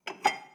Babushka / audio / sfx / Kitchen / SFX_Cup_01.wav
SFX_Cup_01.wav